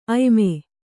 ♪ ayme